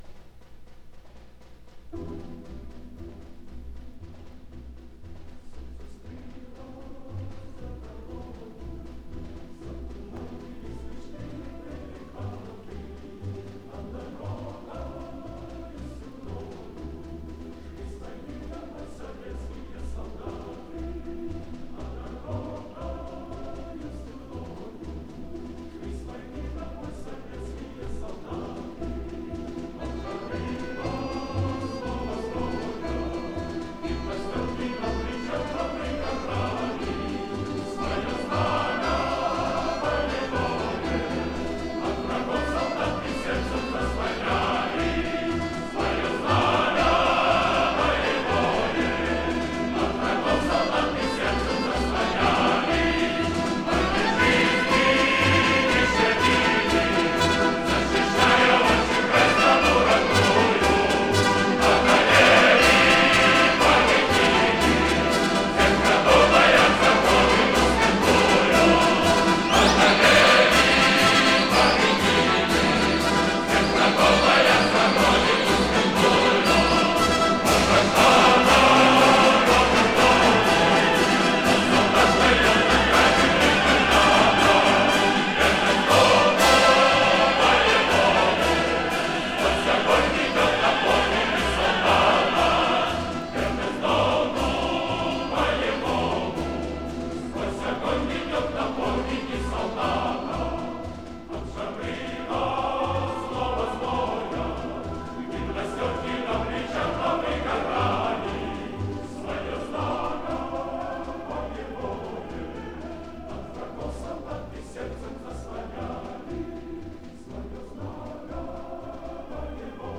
Повышение качества.
С иностранного диска.